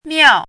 怎么读
miào
miao4.mp3